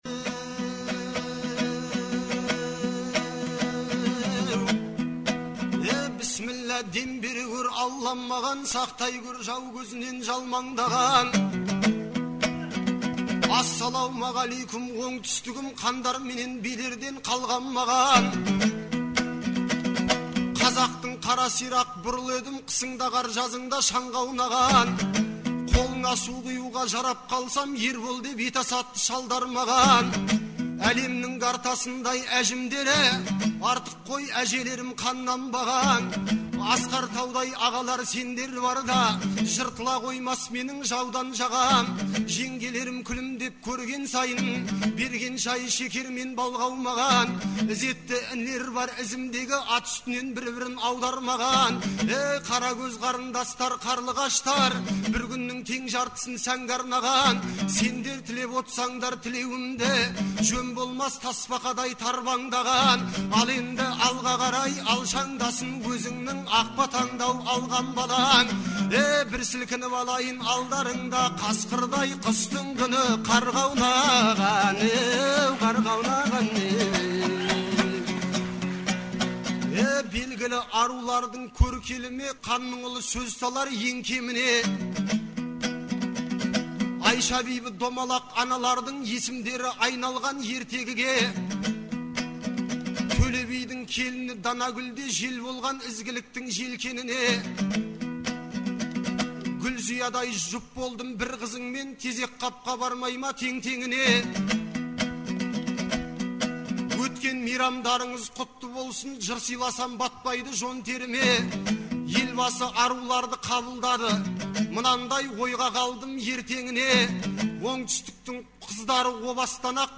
Наурыздың 15-16-сы күндері Шымкентте республикалық «Наурыз» айтысы өтті. 2004 жылдан бері тұрақты өтіп келе жатқан бұл айтыс биылғы жылы Төле бидің 350 және Абылай ханның 300 жылдықтарына арналды.